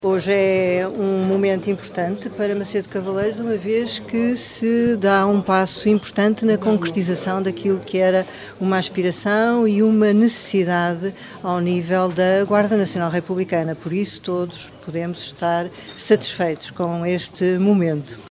Declarações à margem da sessão de assinatura do protocolo para a reabilitação do Posto Territorial da GNR de Macedo de Cavaleiros, que se realizou hoje à tarde no edifício do Centro Cultural.